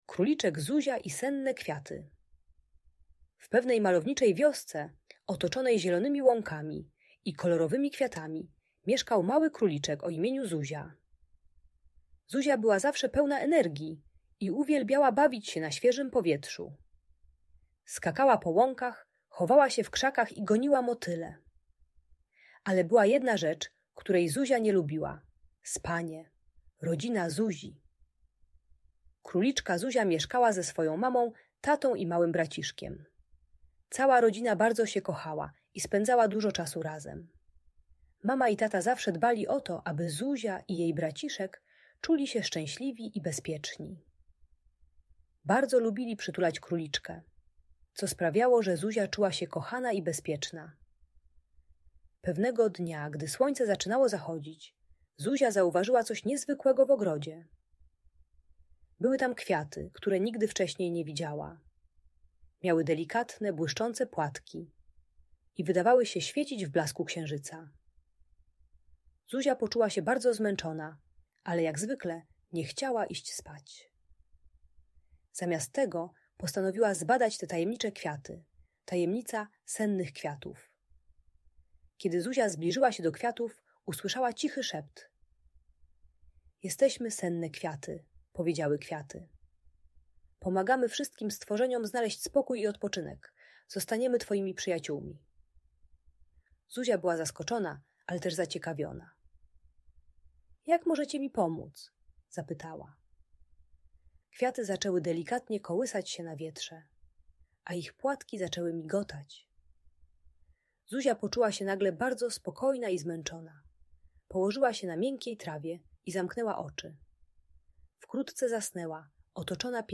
Króliczek Zuzia i Senne Kwiaty - Audiobajka